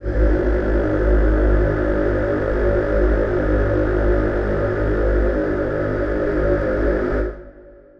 Choir Piano
G#1.wav